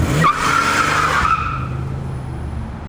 CarScreech2.wav